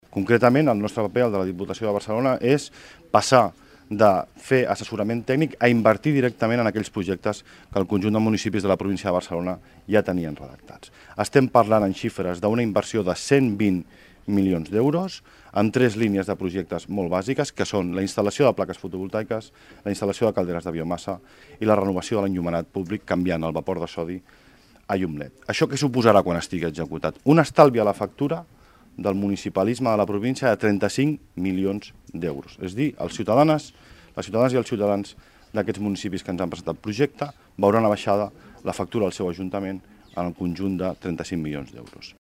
Ajudaran a finançar instal·lacions de plantes fotovoltaiques, calderes de biomassa i milloraran l’eficiència de l’enllumenat públic amb LED. Ho explica Xesco Gomar, diputat d’Acció Climàtica de la diputació de Barcelona.